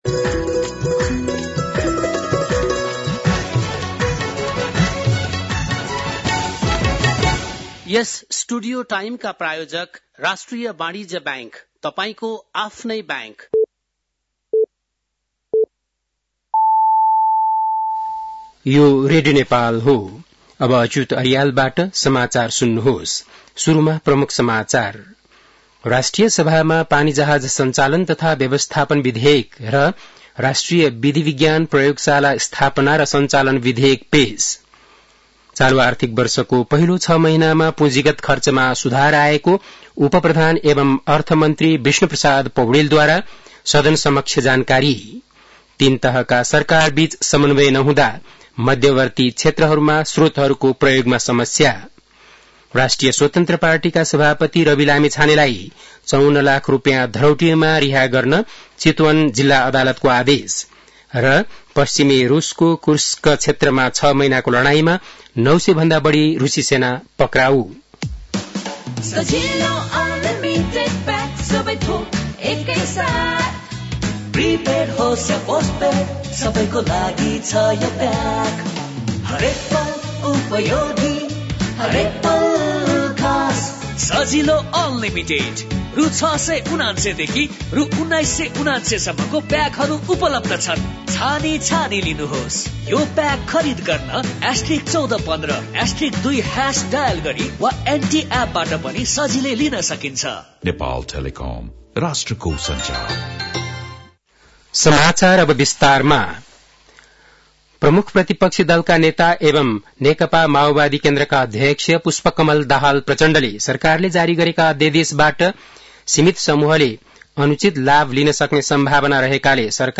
बेलुकी ७ बजेको नेपाली समाचार : २५ माघ , २०८१